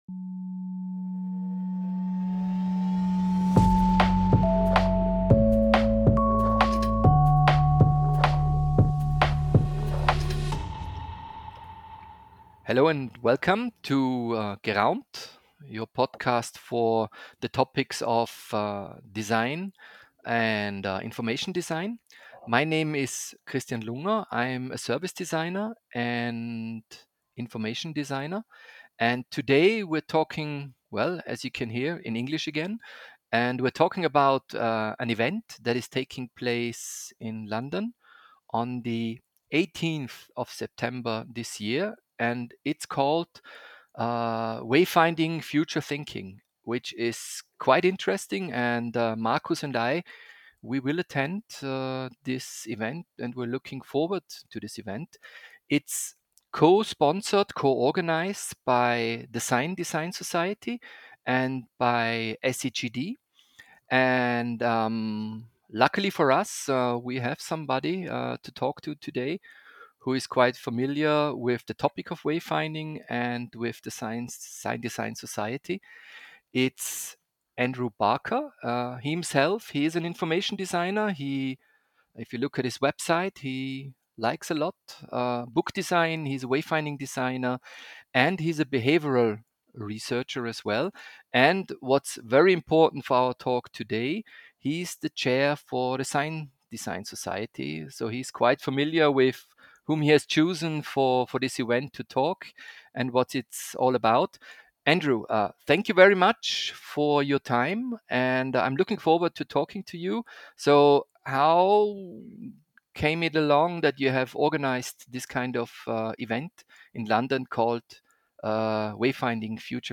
Informative Gespräche geben Einblick in die Welt der räumlichen Orientierung von Menschen in Gebäuden, Orten und im Naturraum.